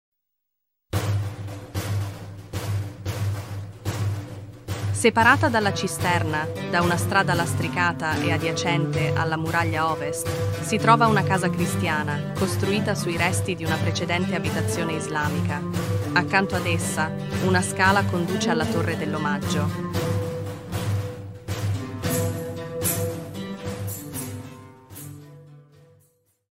Ruta audioguiada